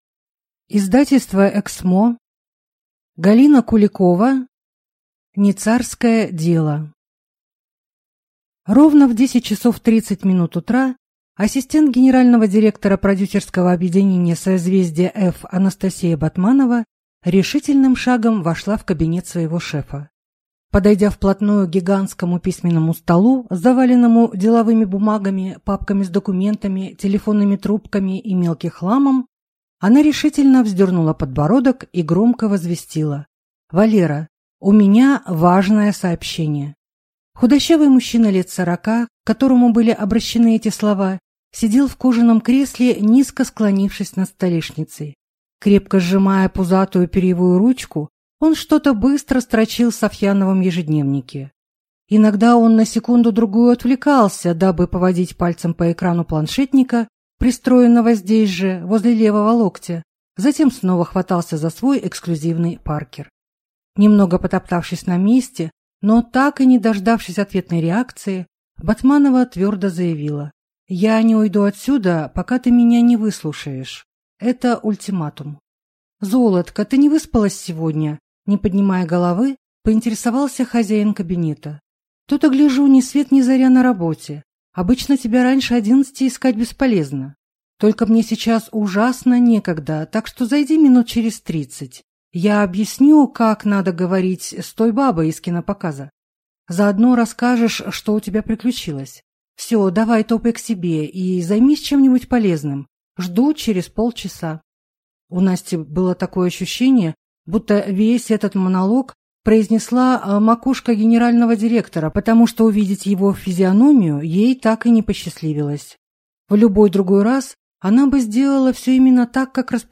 Аудиокнига Не царское дело | Библиотека аудиокниг